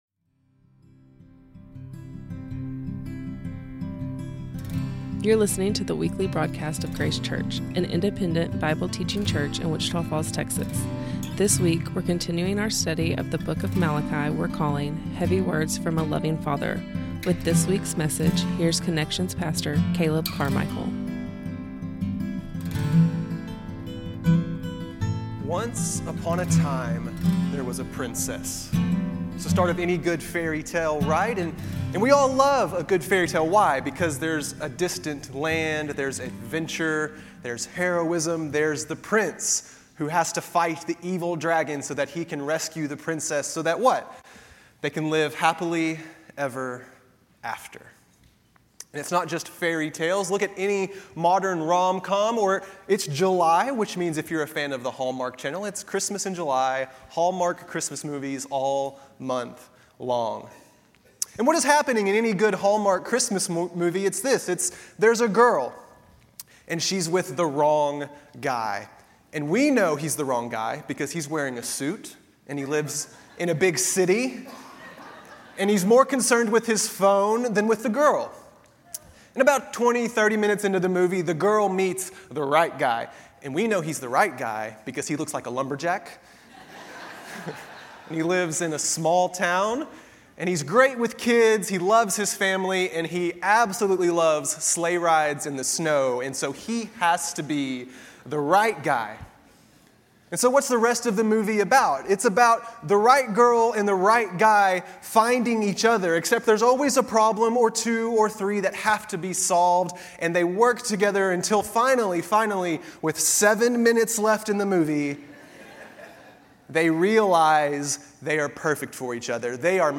A Sunday morning study of the book of Malachi.